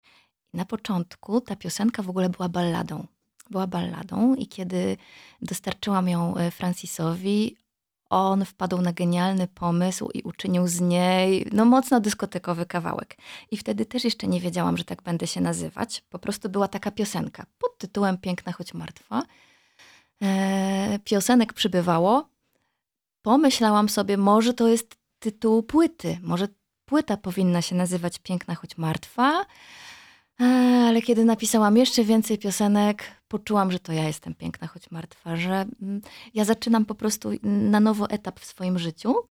W rozmowie w studiu Radio Rodzina aktorka opowiada o genezie projektu muzycznego, sile teatru oraz autorskiej metodzie pracy z ciałem i emocjami.